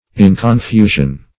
Search Result for " inconfusion" : The Collaborative International Dictionary of English v.0.48: Inconfusion \In`con*fu"sion\n. Freedom from confusion; distinctness.